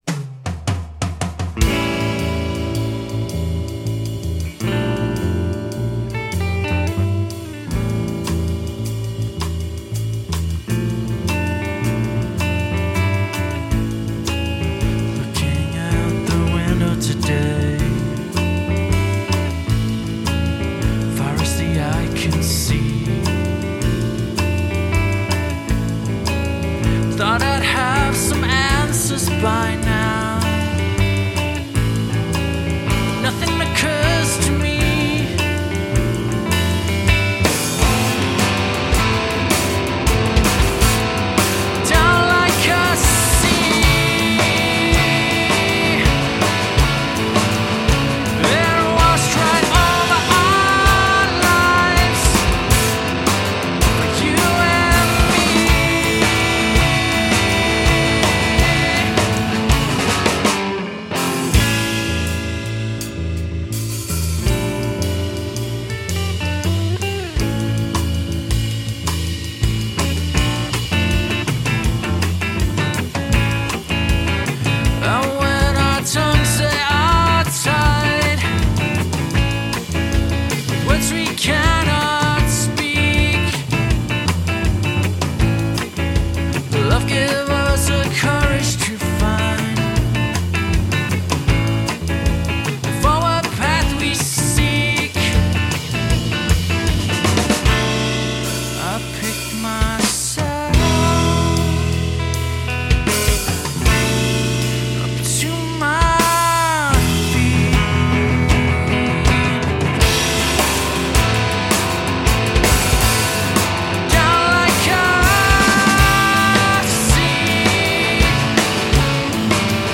on guitar and lead vocals
drummer and backing vocalist